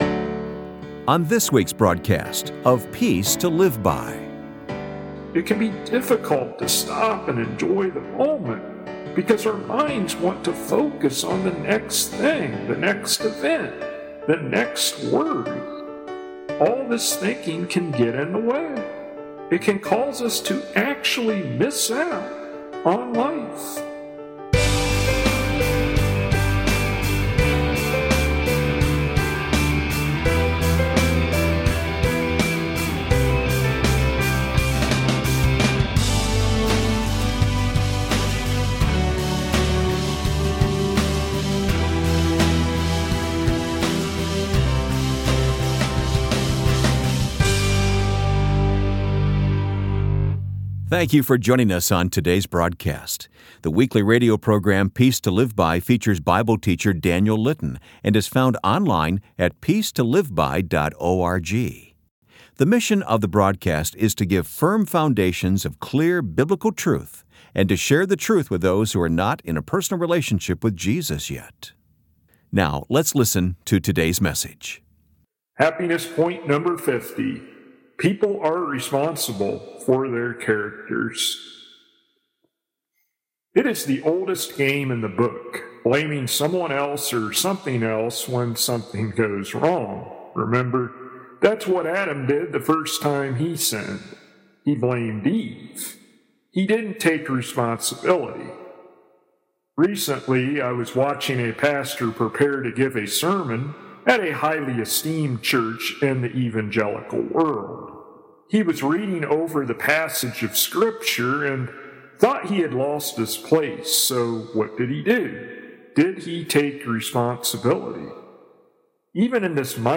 [Transcript may not match broadcasted sermon word for word] Happiness Point #50: People are Responsible for the Characters